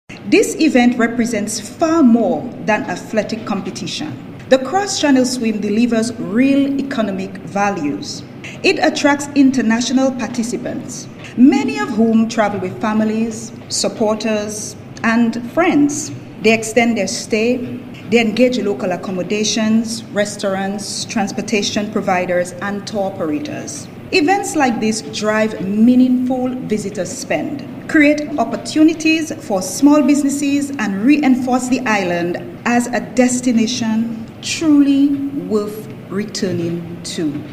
A Media launch was held at Nevis’ Malcolm Guishard Recreational Park at Pinneys for the hosting of the 2026 Nevis to St. Kitts Cross Channel Swim on Friday, January 9th, 2026.